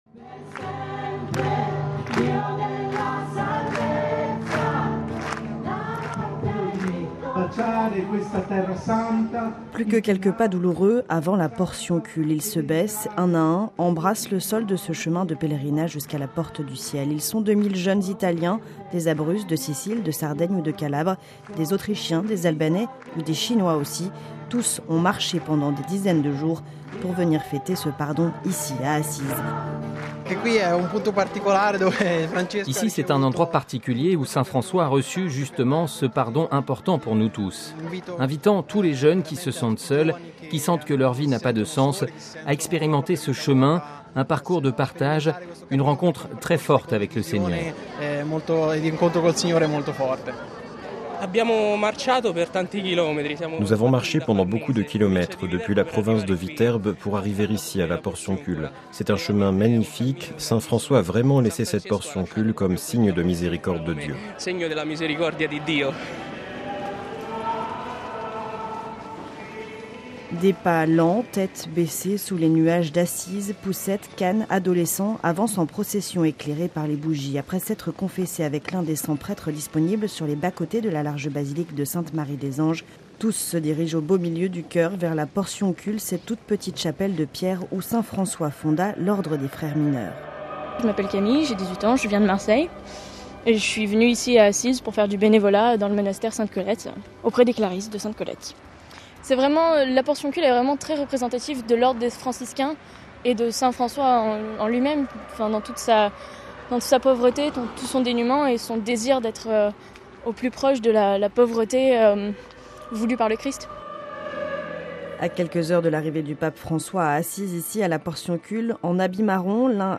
Reportage à la "Portioncule", lieu sacré du Pardon d'Assise